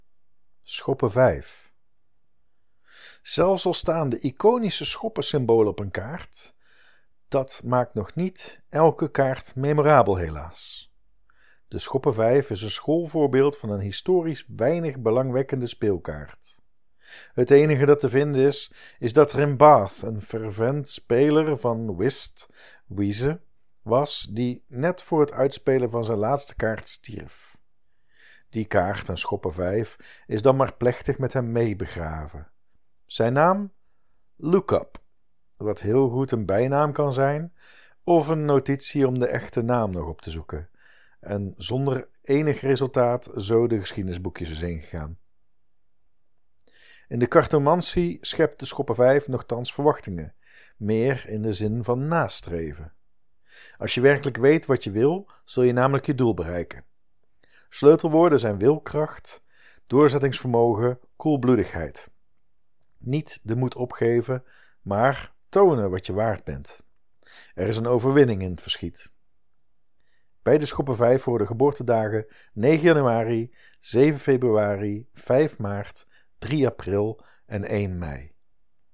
06.05b-Schoppen-vijf-toelichting.mp3